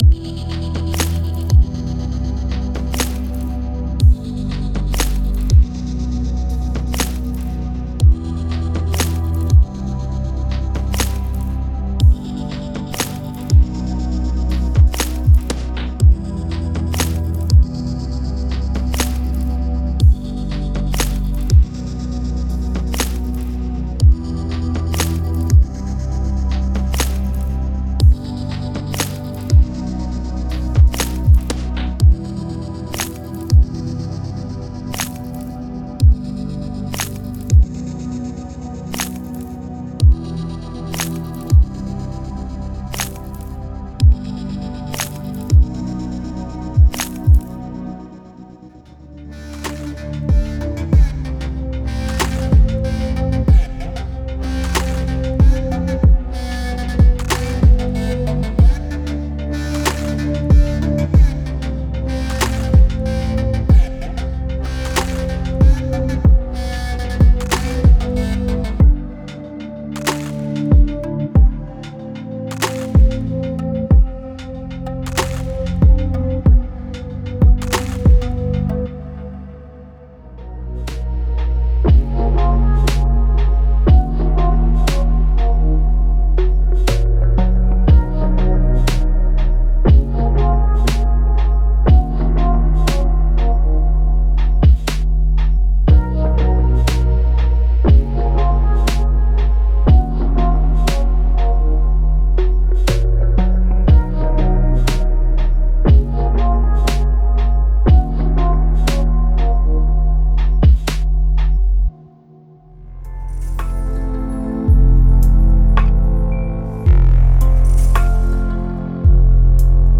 Ambient Chill Out / Lounge
- 5 Mixdown Examples